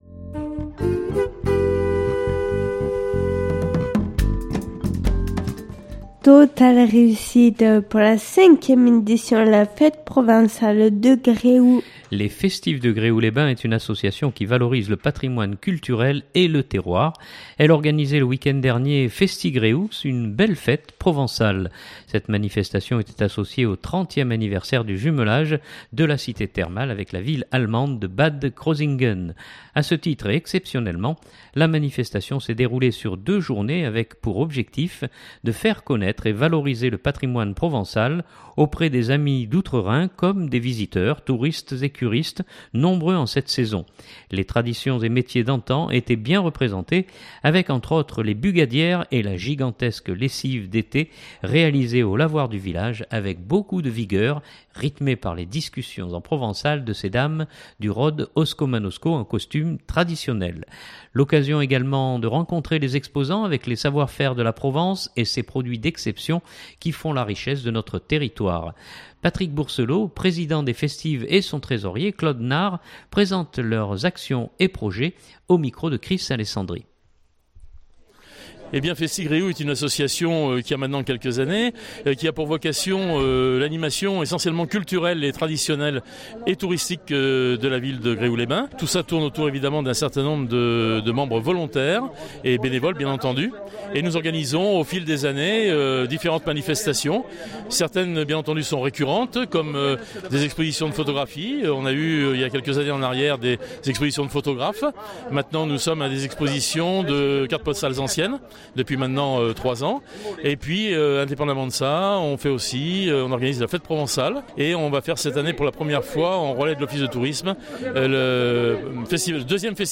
fête Provençale de Gréoux.mp3 (3.43 Mo)